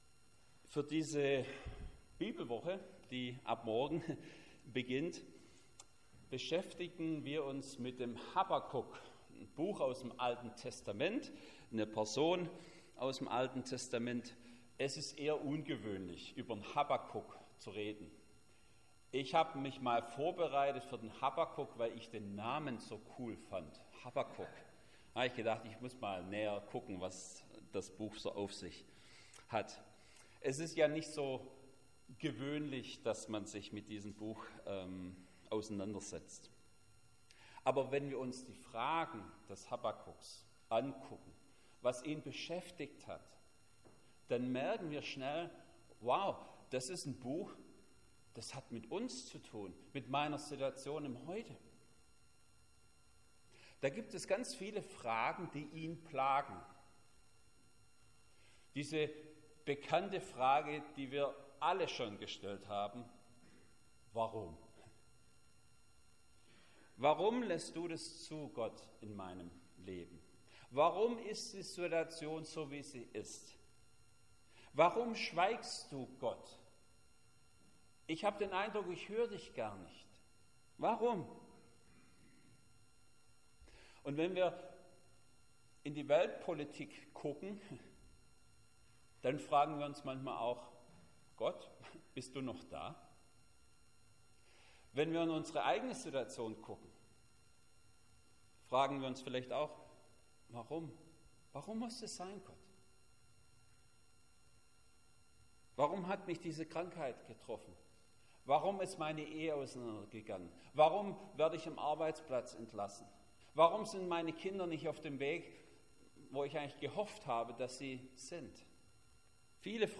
Habakuk 2,4+5 Predigt.mp3